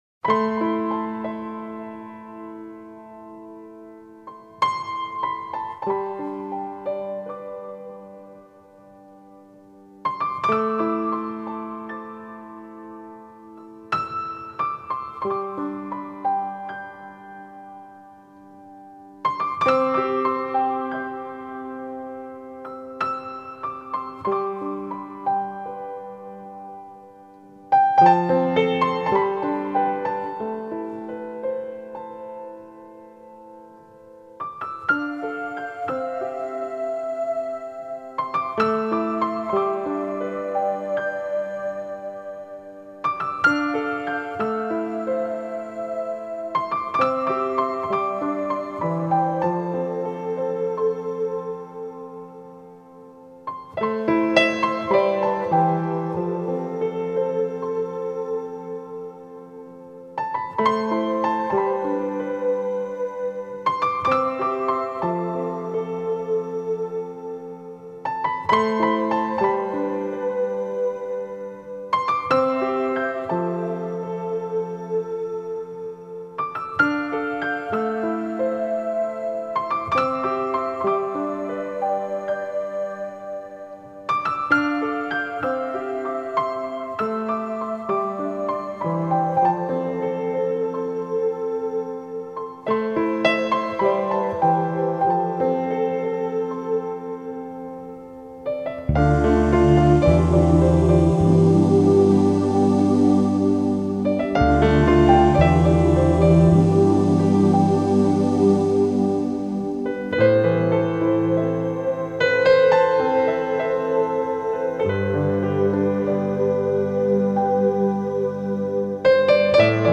NEWAGE